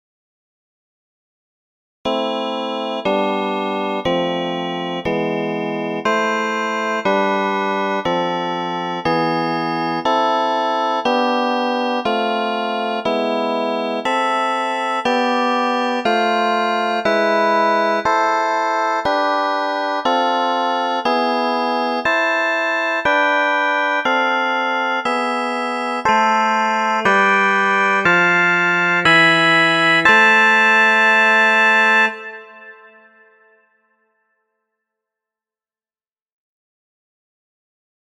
Inversions with Open and Close Voicings
This short harmonic idea is played using a variety of inversions and voicings.
The progression is played seven times through.
The last repetition is in a four voice texture with the bass doubling the root of each chord.
The chord progression in roman numerals is I - VII - VI - V, the key is A minor (mixture of natural and harmonic).
CloseOpen.mp3